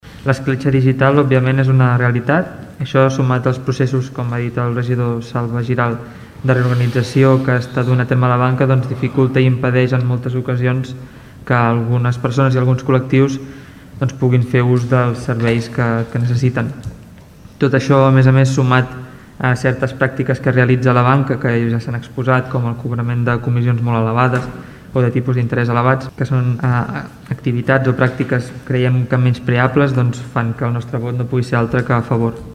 El Ple de l’Ajuntament de Tordera va aprovar una moció per controlar la digitalització dels serveis bancaris.
Des de la CUP, el regidor Oriol Serra critica l’escletxa digital i recorda que cal apropar els serveis a tothom.